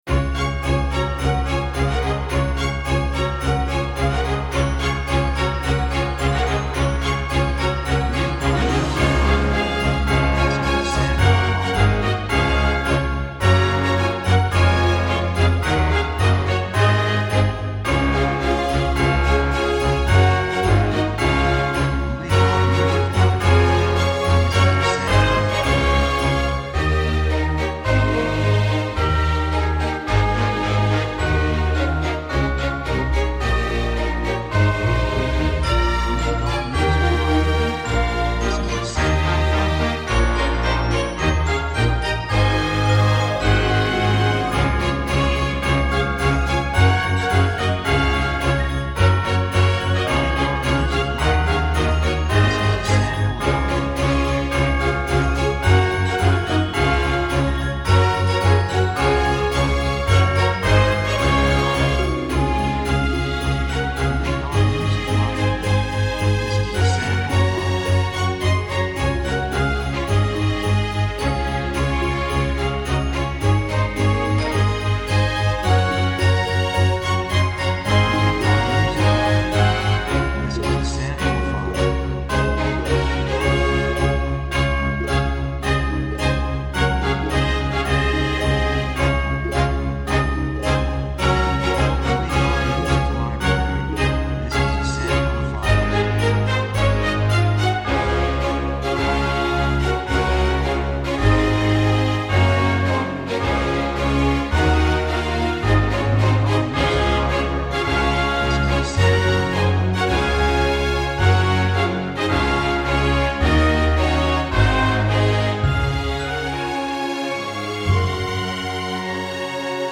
雰囲気幸せ, 高揚感, 喜び, 感情的
曲調ポジティブ
楽器ベル, ハープ, オーケストラ
サブジャンルクリスマス, オーケストラ
テンポやや速い
3:13 108 プロモ, 季節物, スコア